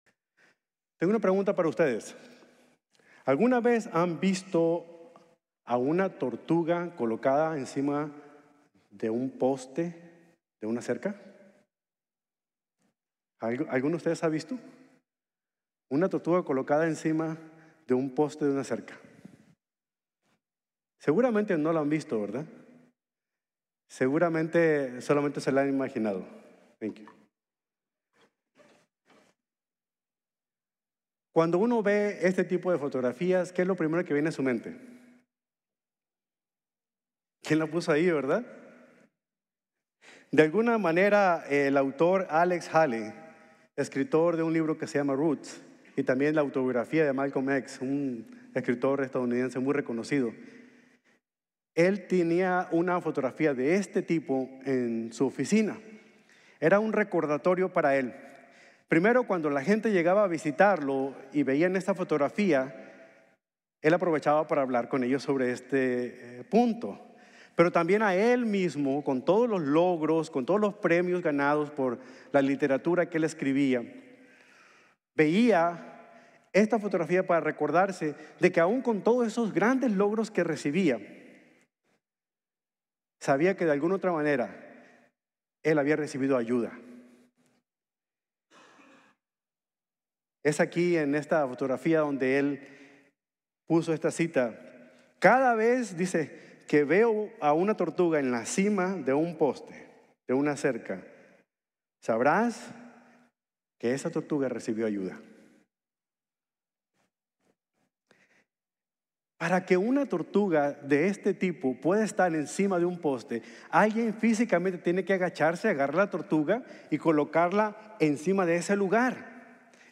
Solo Por Fe | Sermón | Grace Bible Church